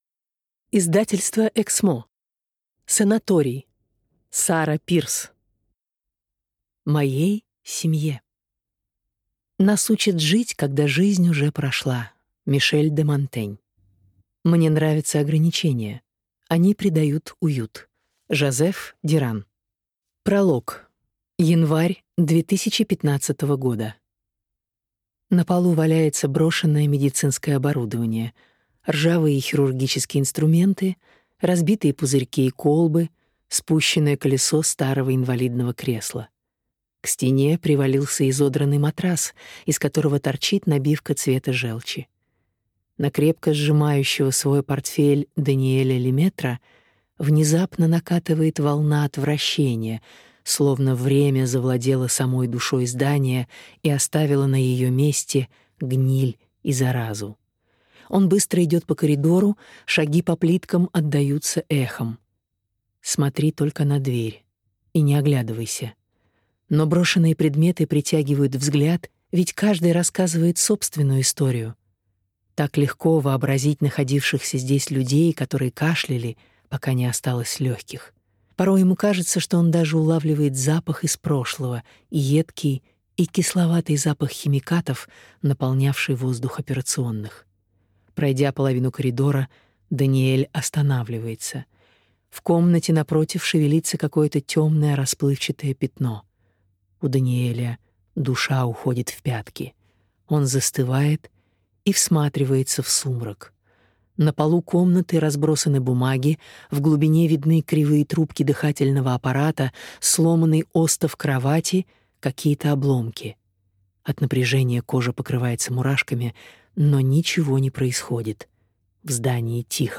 Аудиокнига Санаторий | Библиотека аудиокниг